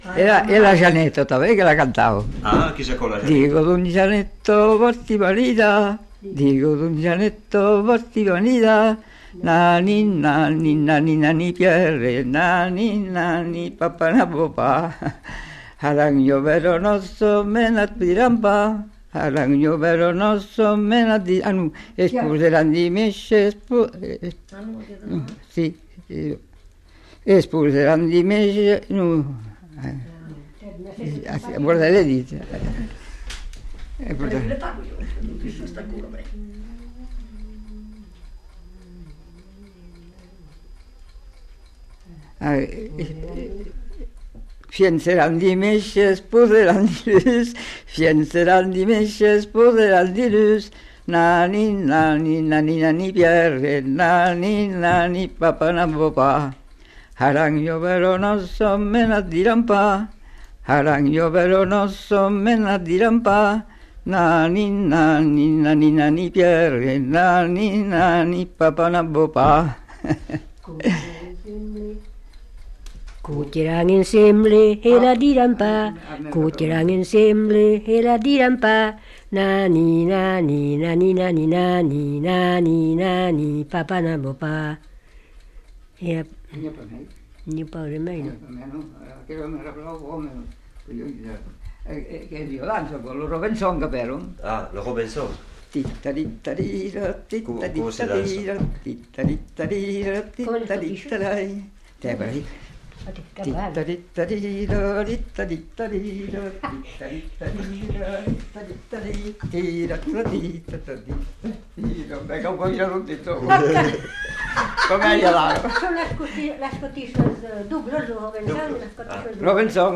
Scottish double (fredonné)